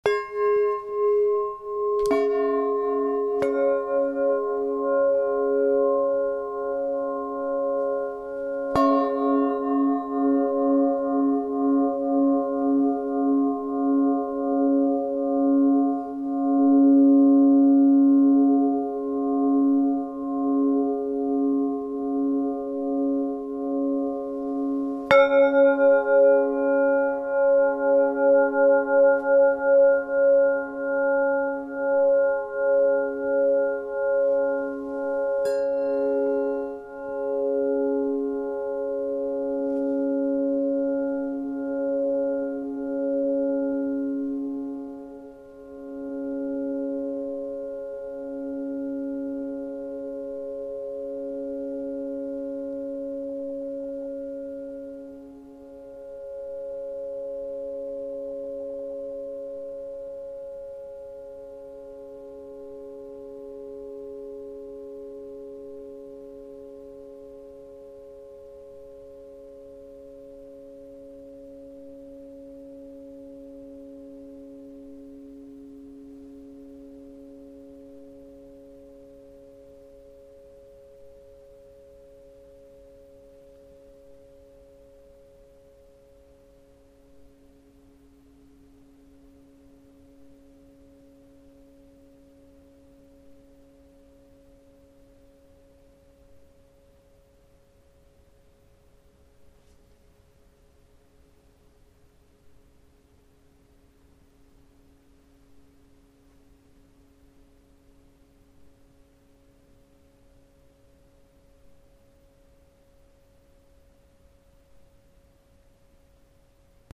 Klangmobile «Sonne und Mond»
cis1-gis1-cis2 incl. Schlägel
So entschied ich mich für die Tonfolge cis’ – gis’ – cis’’, die zusammen sehr schön harmonisch klingen,
erzeugt es einen intensiven Klang, der bis zu zwei Minuten anhält
und durch die Drehbewegung eine interessante Schwebung bekommt.
klangmobile_sonne+mond.mp3